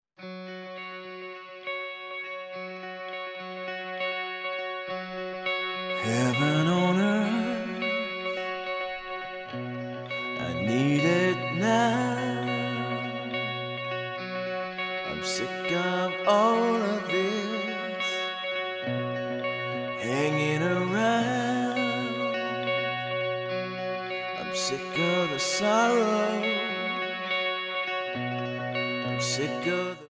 Tonart:C# Multifile (kein Sofortdownload.
Die besten Playbacks Instrumentals und Karaoke Versionen .